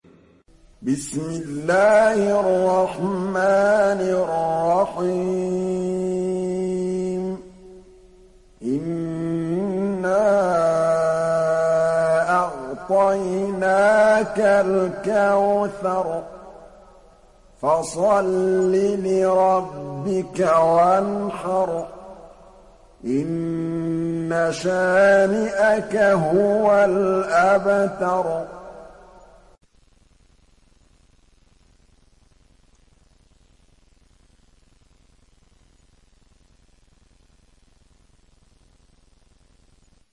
সূরা আল-কাউসার ডাউনলোড mp3 Muhammad Mahmood Al Tablawi উপন্যাস Hafs থেকে Asim, ডাউনলোড করুন এবং কুরআন শুনুন mp3 সম্পূর্ণ সরাসরি লিঙ্ক